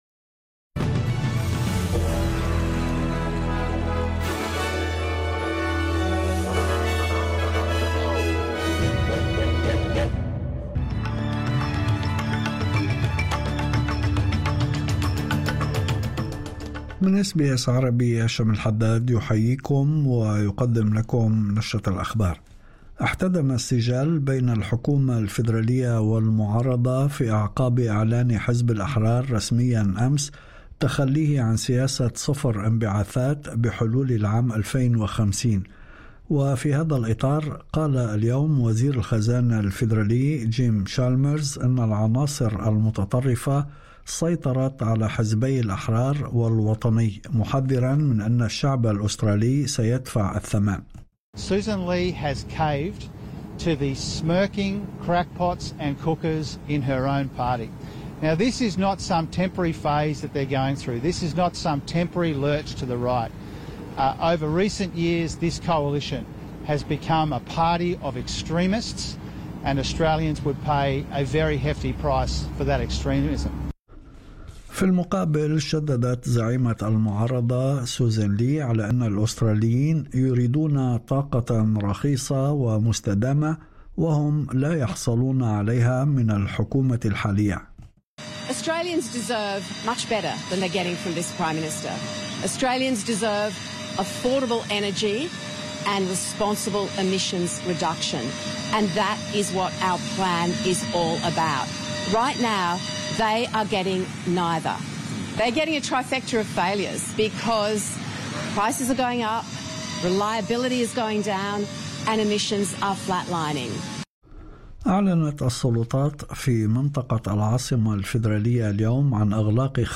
نشرة أخبار الظهيرة 14/11/2025